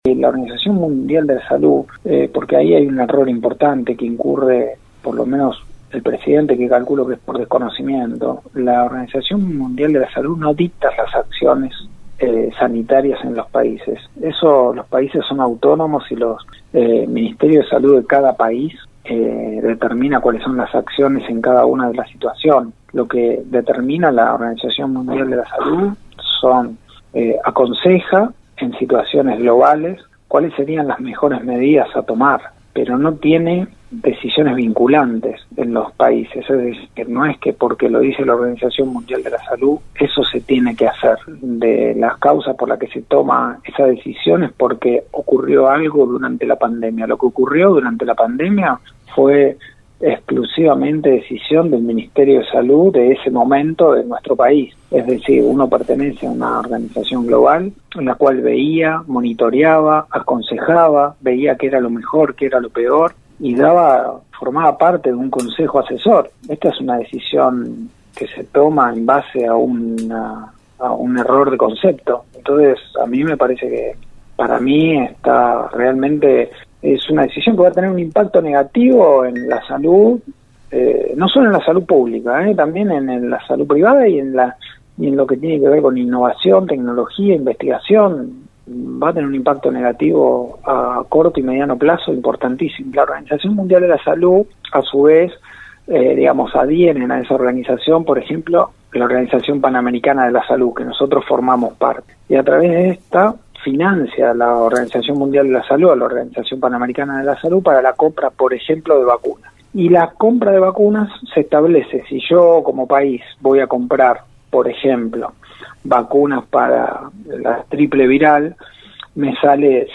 Universo Noticias diálogo con el secretario de salud de la Municipalidad de Mercedes el Dr Néstor Pisapia y escucha su opinión en la siguiente nota.